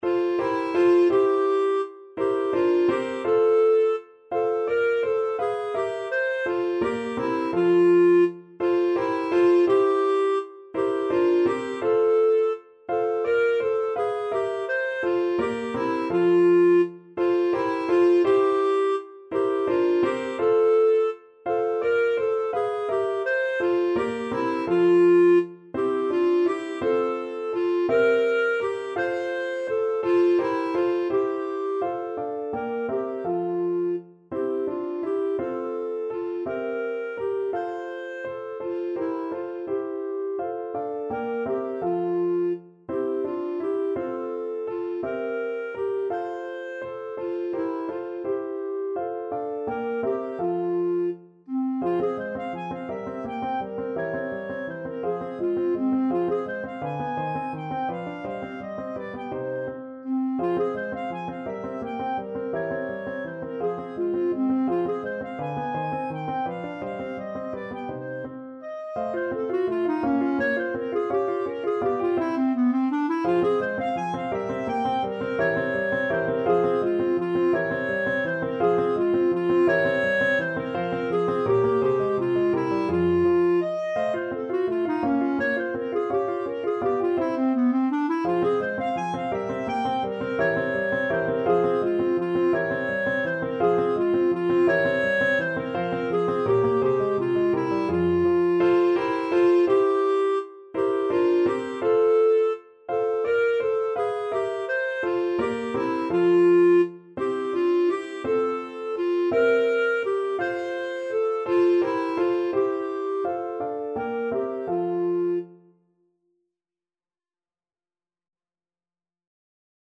Lustig (Happy) .=56
3/8 (View more 3/8 Music)
Classical (View more Classical Clarinet Music)